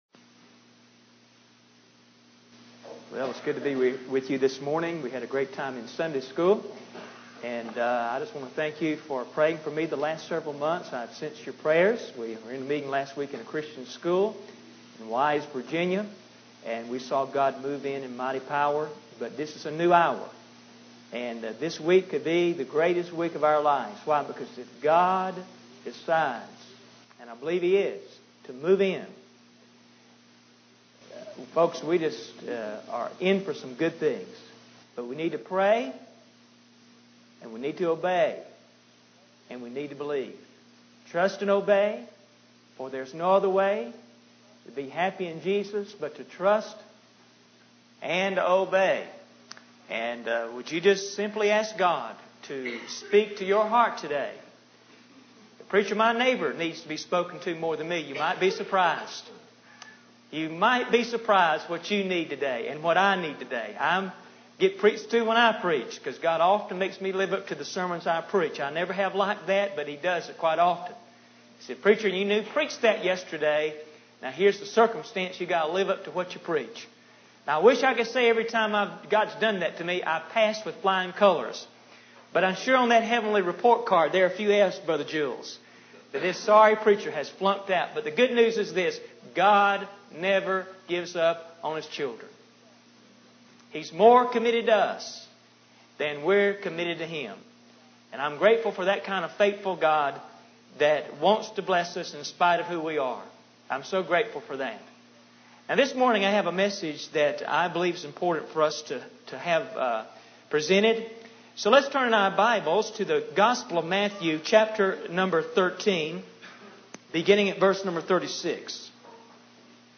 In this sermon, the preacher emphasizes the concept of judgment and the end of the world. He describes how the Son of Man will send his angels to gather all those who offend and do iniquity, casting them into a furnace of fire where there will be weeping and gnashing of teeth.